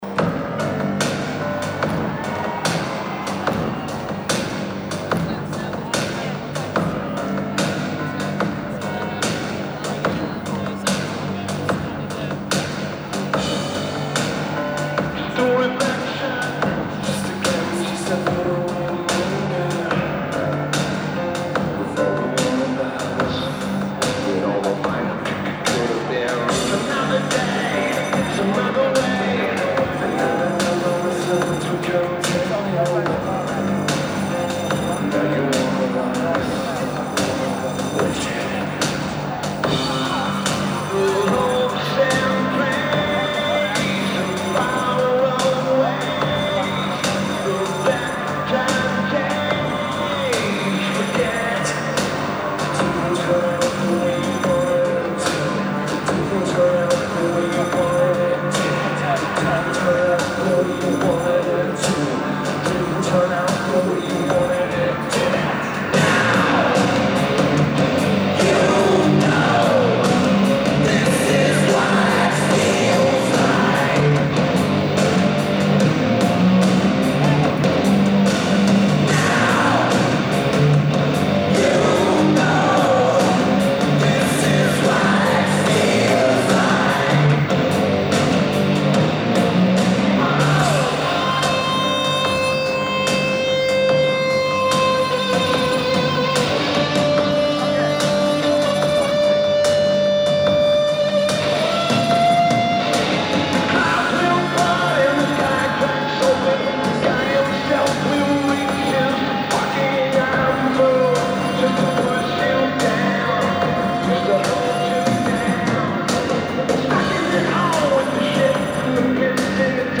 O2 Arena
Lineage: Audio - AUD (iPhone 5S)